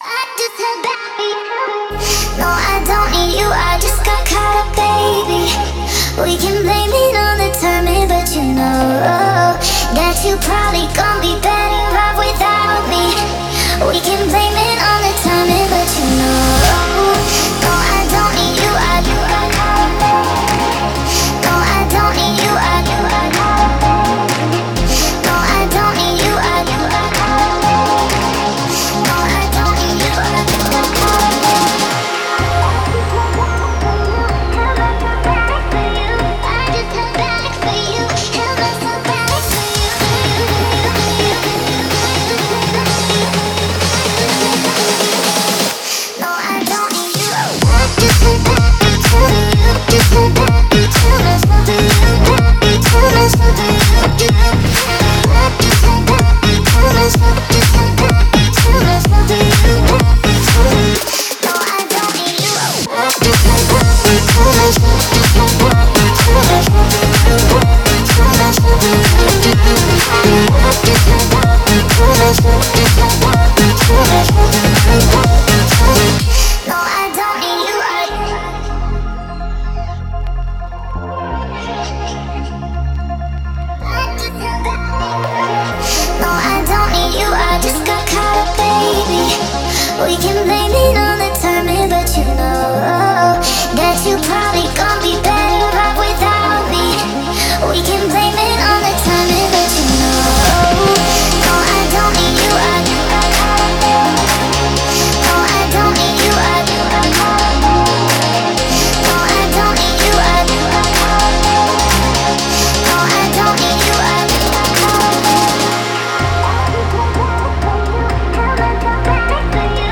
мощная поп-рок композиция